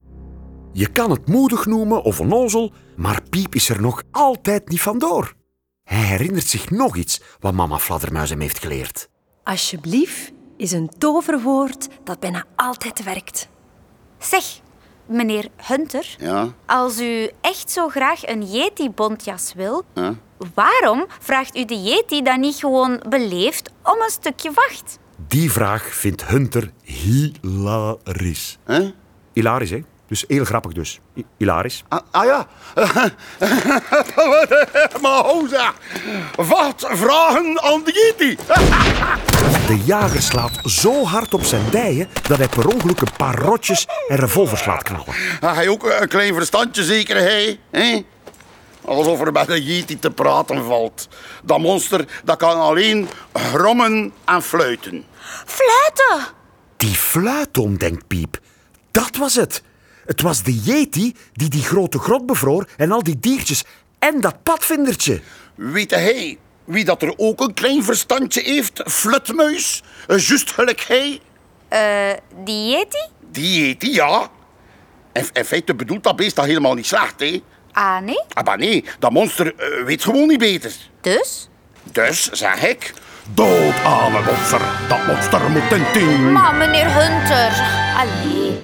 Met de stemmen van Dominique Van Malder, Evelien Bosmans, Liesa Van der Aa, Wim Willaert, Noémie Wolfs, Tom Vermeir en Lien De Graeve.
De rollen worden ingesproken door de béste acteurs en in bijhorend prentenboek staat ook een voorleestekst.